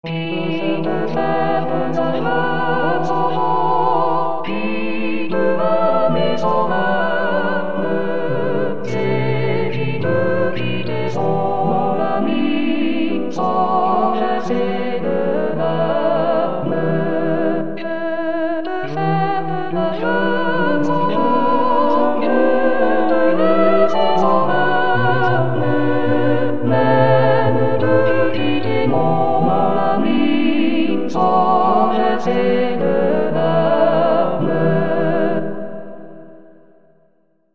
Tutti.mp3